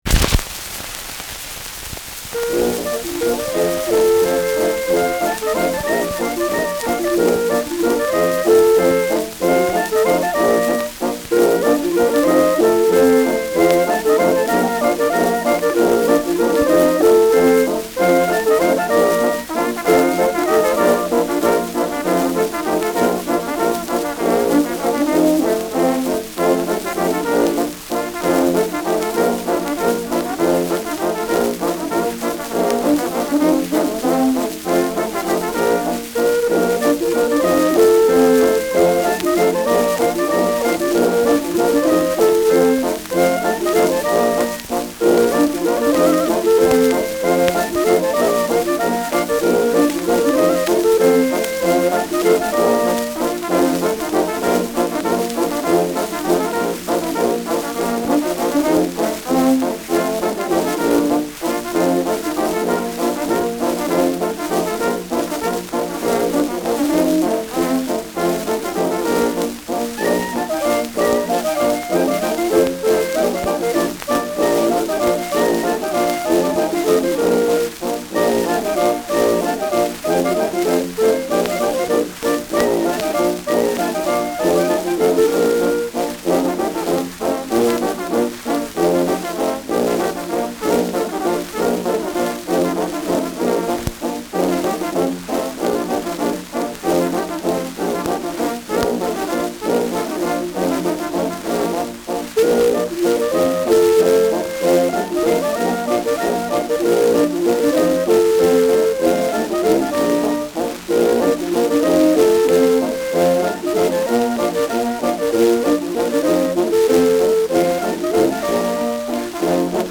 Schellackplatte
Tonrille: Kratzer Durchgehend Leicht : Kratzer 9 Uhr Stärker
Abgespielt : Gelegentlich leichtes Knacken : Stärkeres Grundrauschen